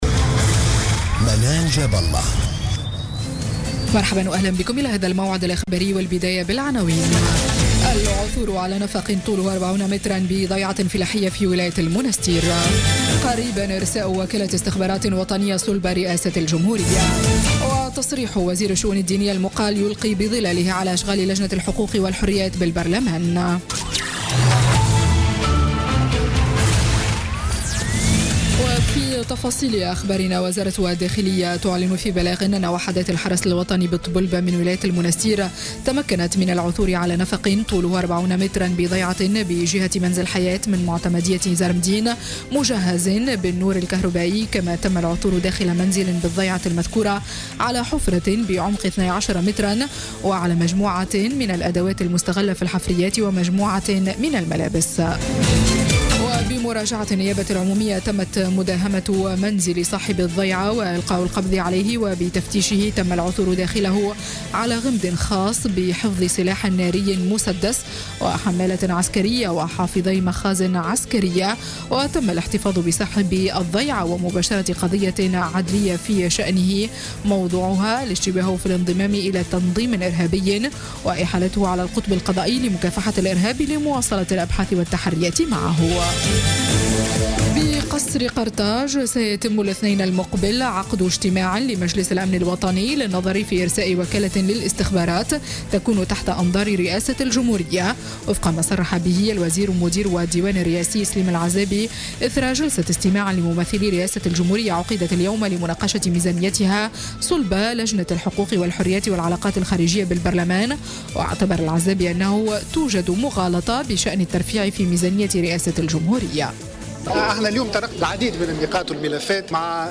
نشرة أخبار السابعة مساء ليوم الجمعة 4 نوفمبر 2016